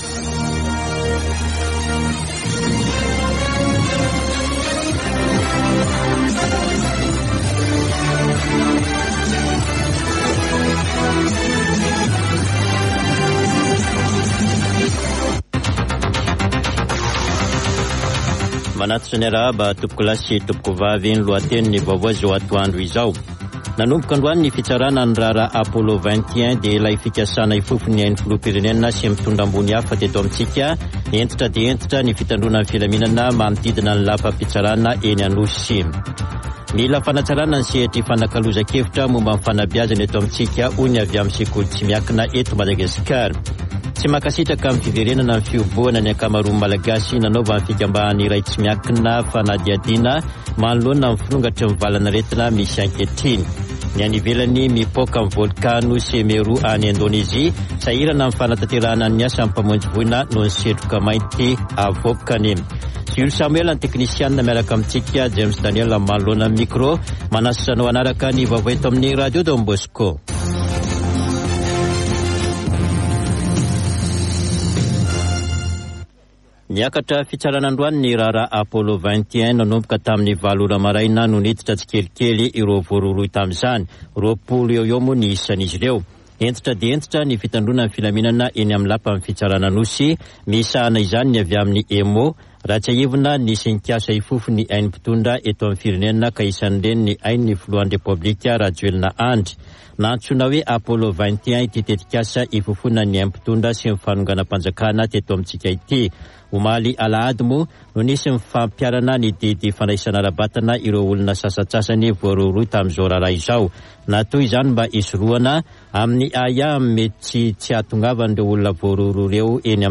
[Vaovao antoandro] Alatsinainy 06 desambra 2021